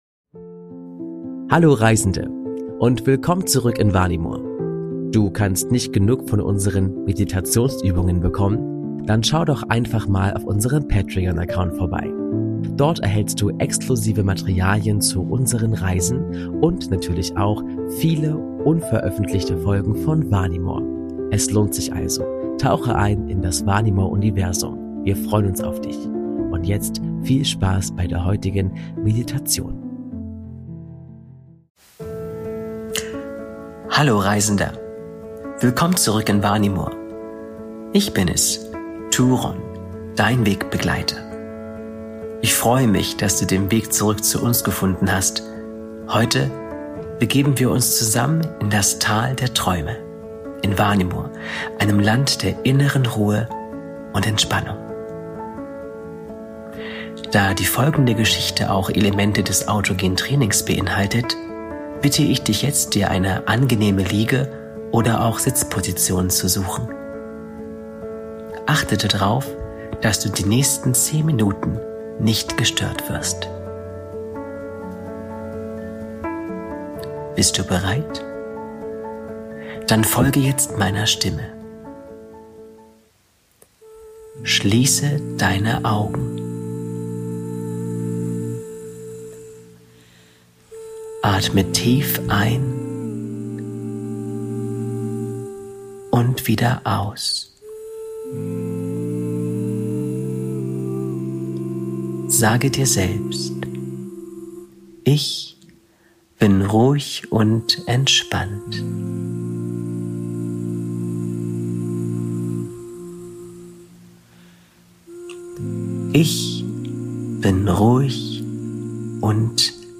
Autogenes Training/Entspannungsgeschichte: Das Tal der Träume ~ Vanimor - Seele des Friedens Podcast